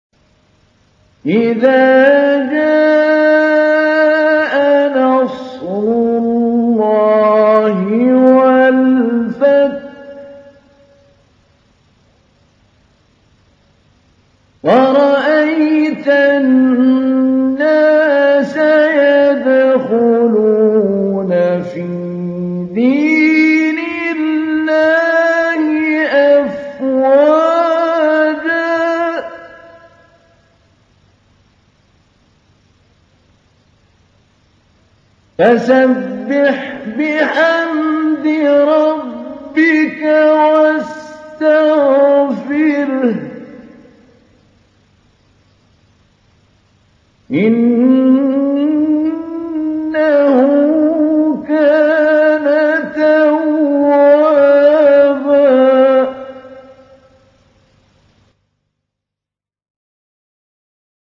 تحميل : 110. سورة النصر / القارئ محمود علي البنا / القرآن الكريم / موقع يا حسين